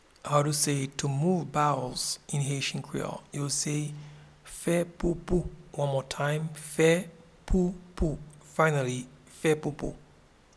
Pronunciation and Transcript:
To-move-bowels-Fe-poupou.mp3